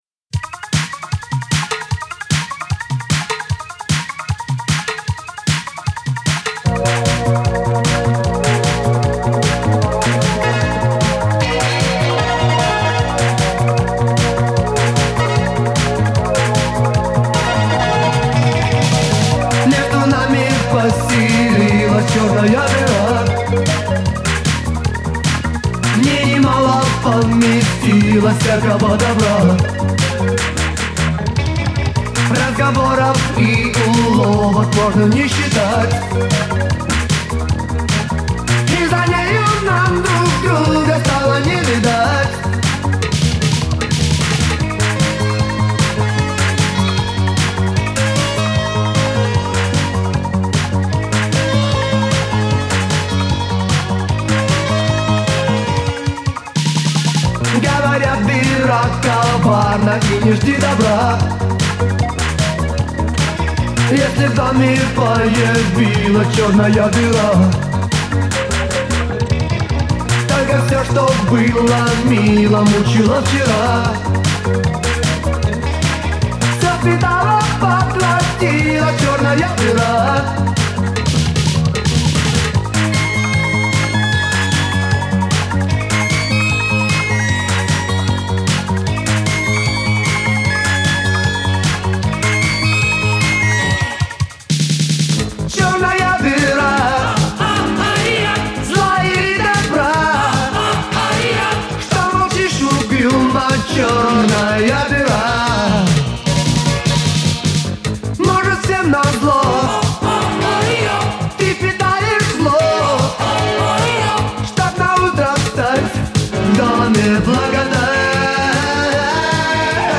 Файл в обменнике2 Myзыкa->Русский рок
Жанры: рок-музыка, блюз, джаз, фьюжн,
поп-рок, новая волна, хард-рок, синтипоп